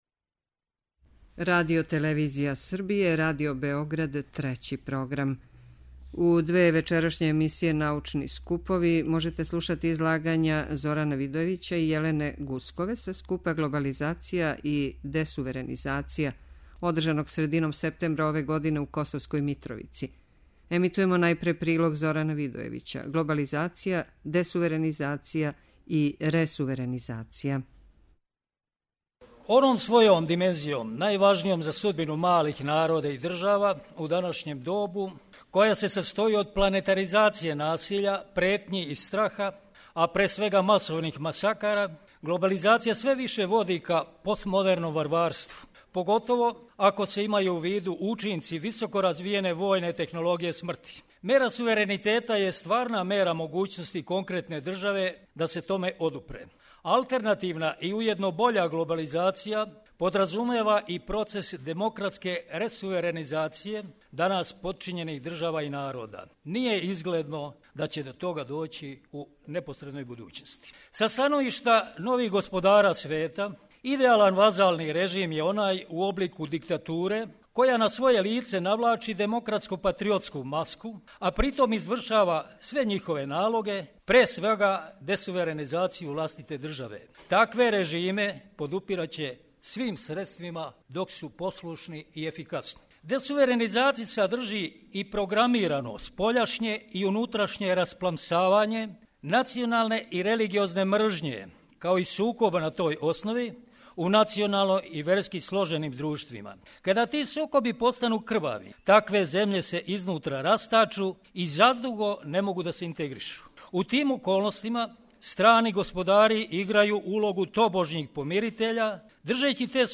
излагање